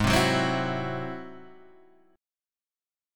G# Augmented 7th